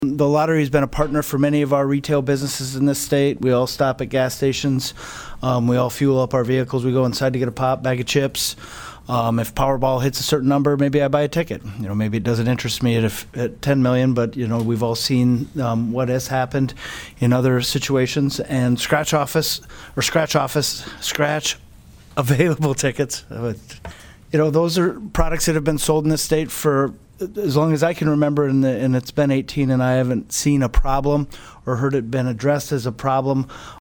PIERRE, S.D.(HubCityRadio)- The South Dakota Senate Commerce & Energy Committee heard testimony on SB203 on Thursday.